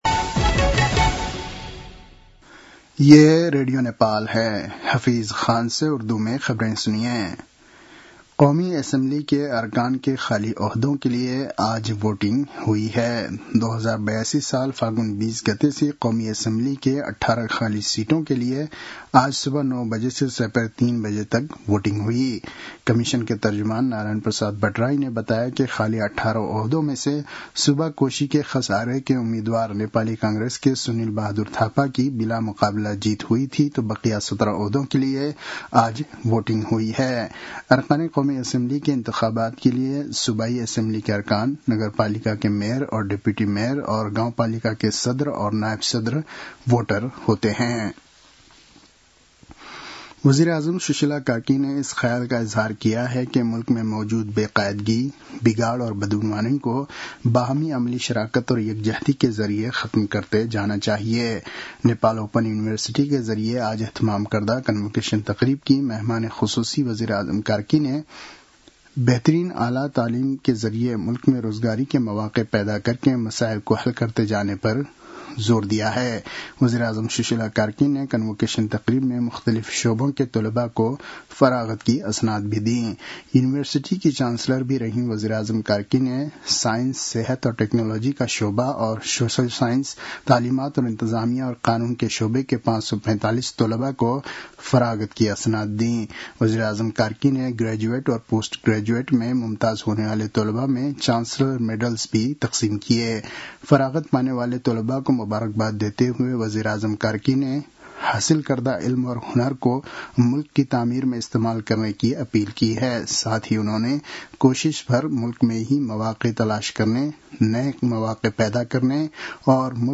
उर्दु भाषामा समाचार : ११ माघ , २०८२
Urdu-news-10-11.mp3